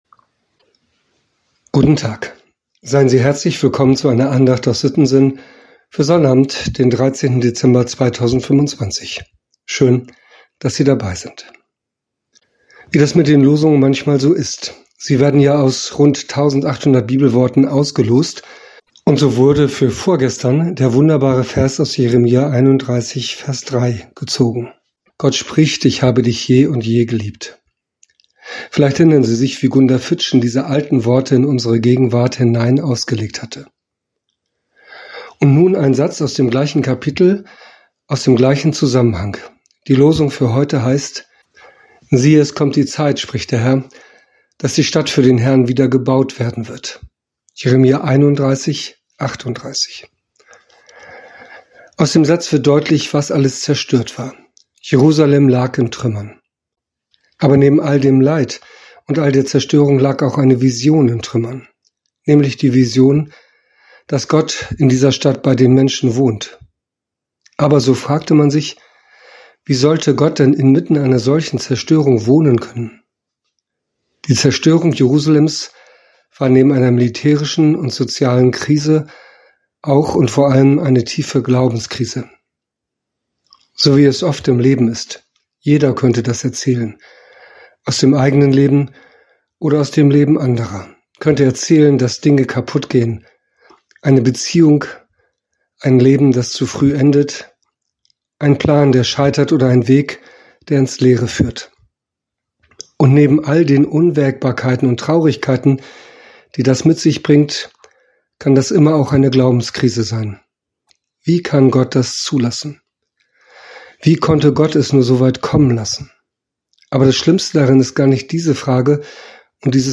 Audio-Dateien der Andachten (Herrnhuter Losungen)